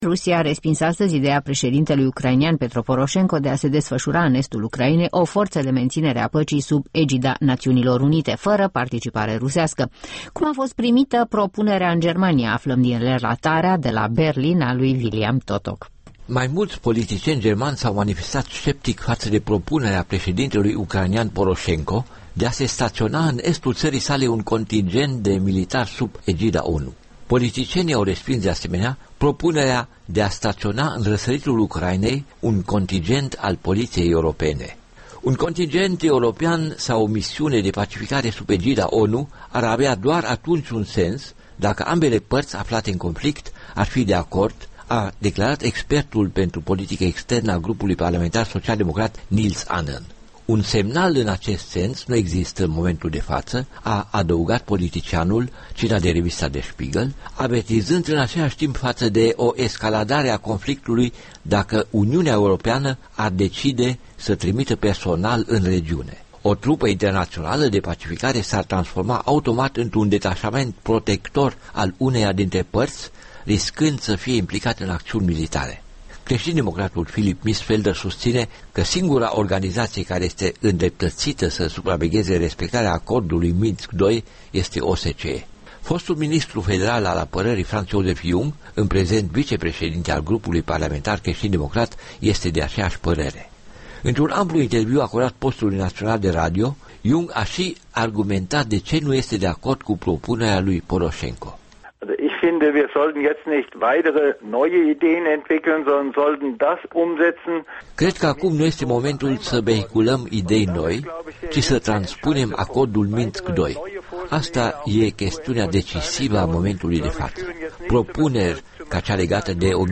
În actualitatea internațională: Ucraina - o corespondență de la Berlin